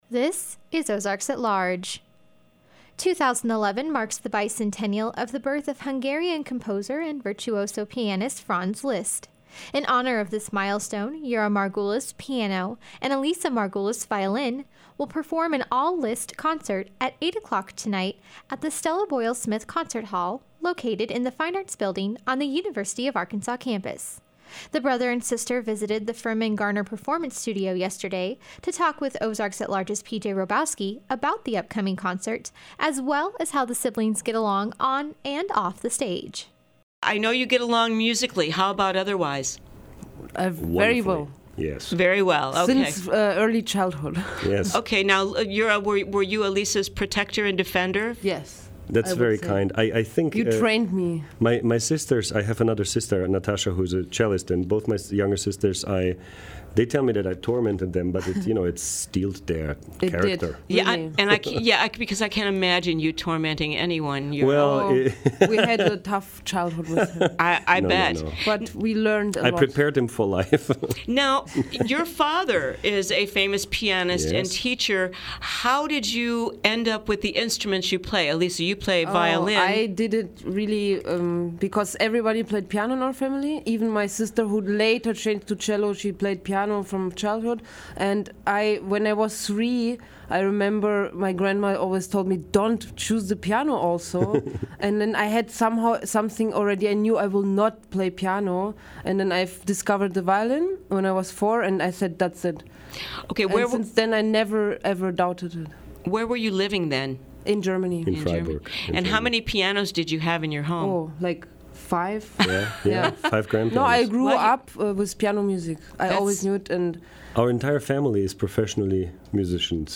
stop by the Firmin-Garner Performance Studio to perform a piece from their concert tonight at the University of Arkansas